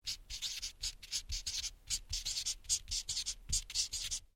Звуки маркеров
Шуршание маркера по бумаге